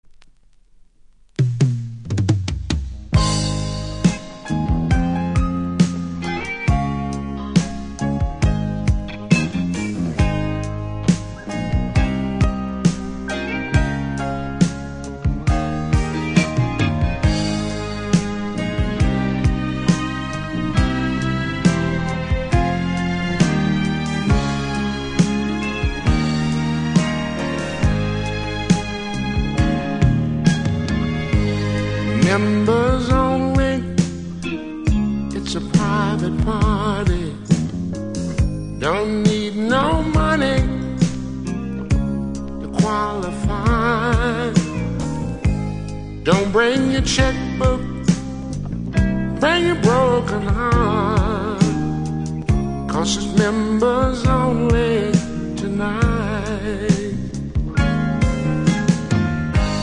こちらサイドは少しセンターずれありますので試聴で確認下さい